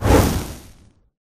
Pele_A01_Fireball_01.m4a